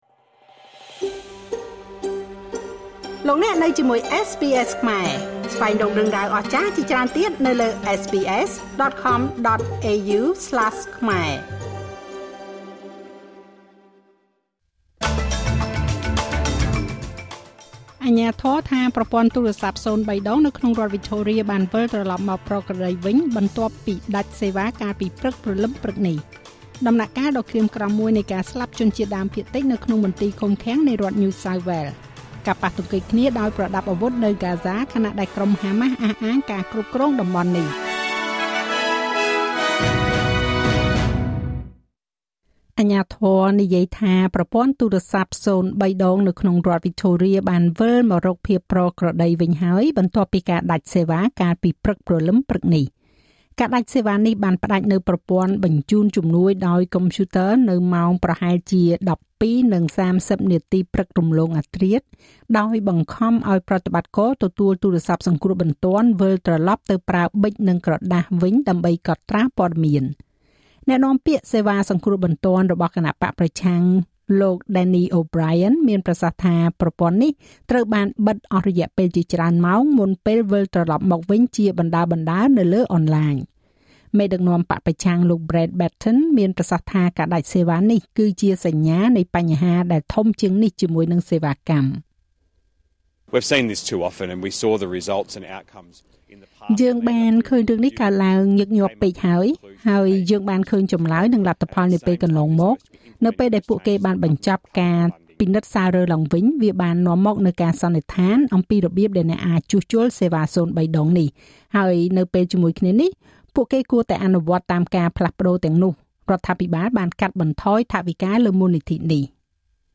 នាទីព័ត៌មានរបស់SBSខ្មែរ សម្រាប់ថ្ងៃពុធ ទី១៥ ខែតុលា ឆ្នាំ២០២៥